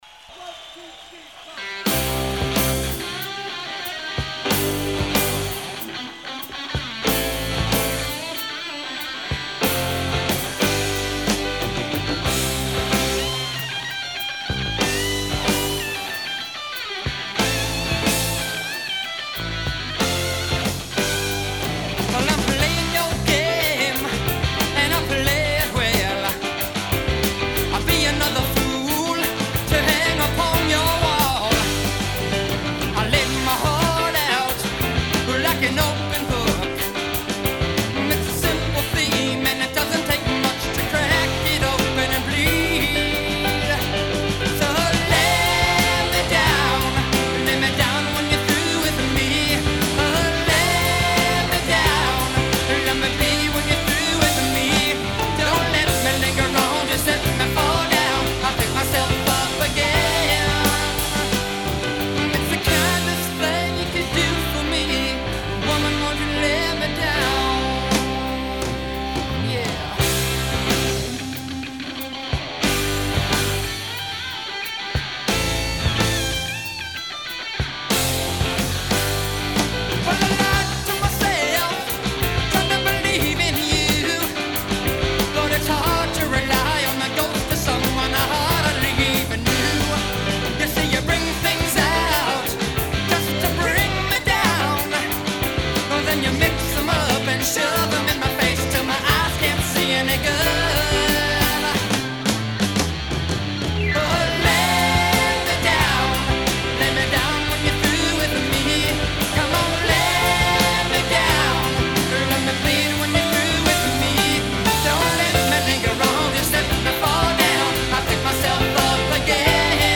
The song you embedded sounds rather Meatloaf to me.